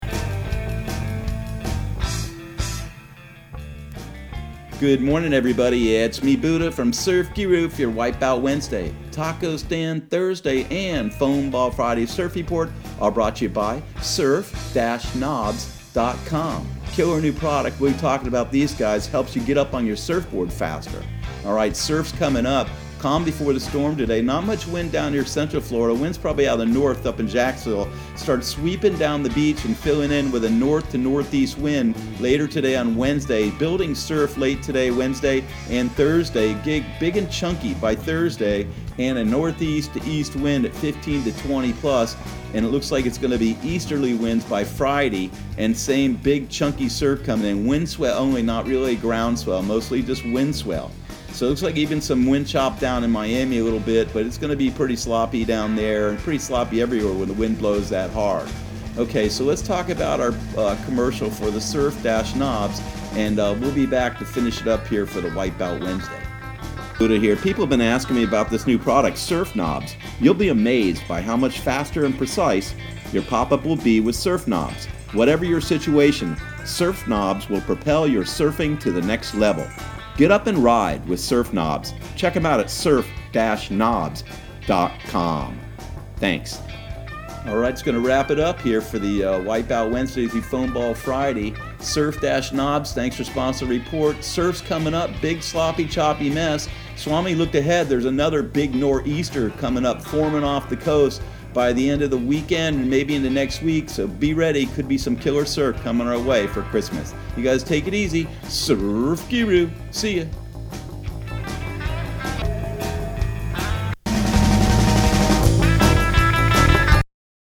Surf Guru Surf Report and Forecast 12/18/2019 Audio surf report and surf forecast on December 18 for Central Florida and the Southeast.